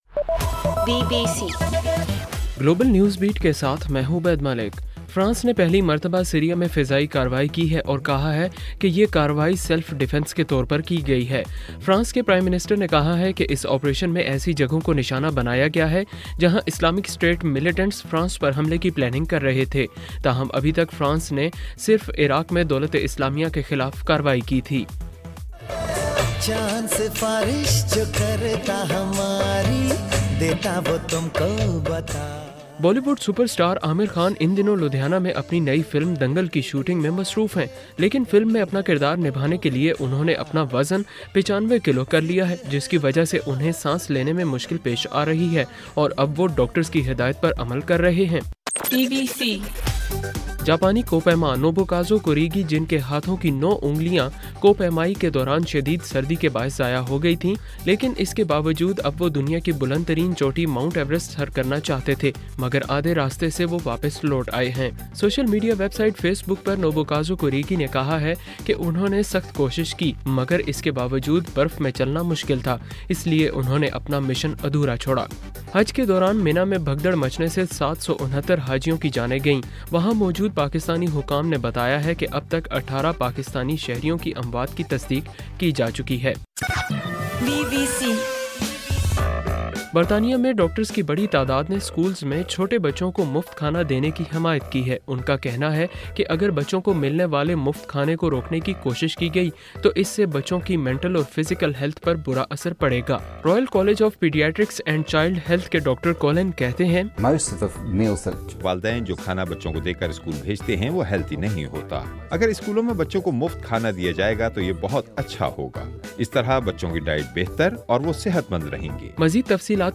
ستمبر 27: رات 8 بجے کا گلوبل نیوز بیٹ بُلیٹن